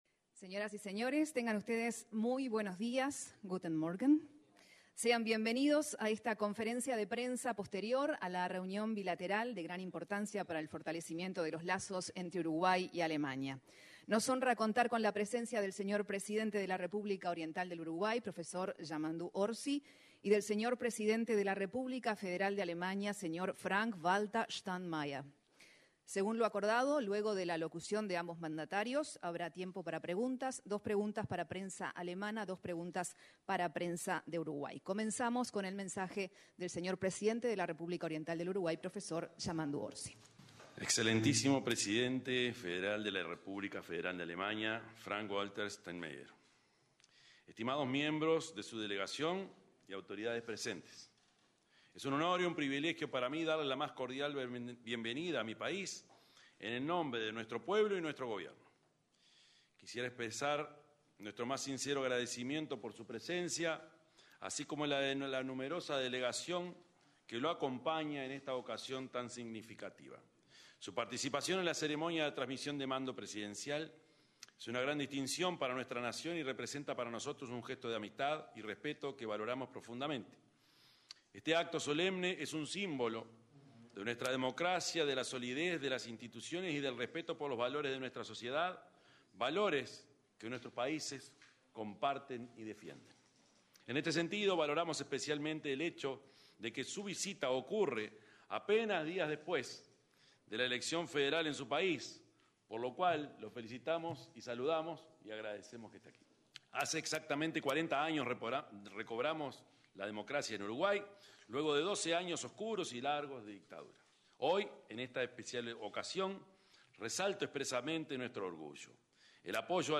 Conferencia de prensa de Uruguay y Alemania
Este 2 de marzo, los mandatarios de Uruguay y Alemania se expresaron en una conferencia de prensa en el edificio José Artigas.